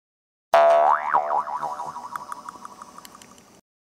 Boing Sound Button - Botão de Efeito Sonoro